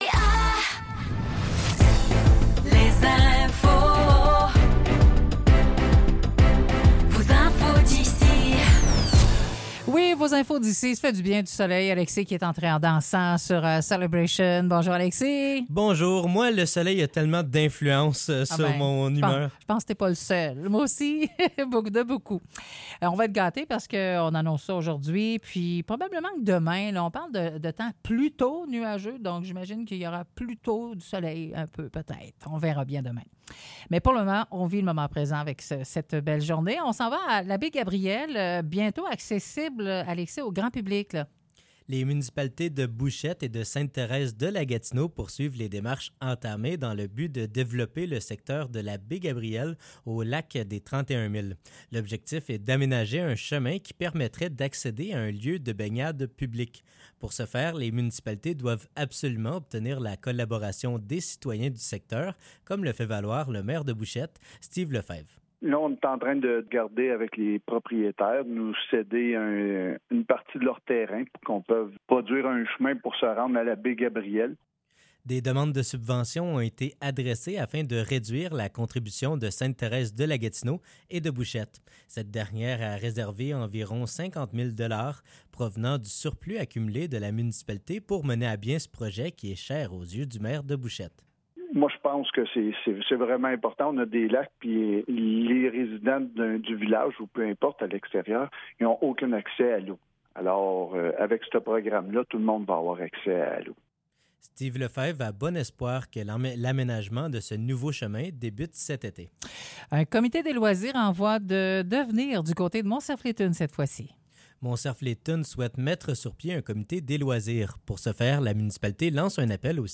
Nouvelles locales - 6 février 2024 - 10 h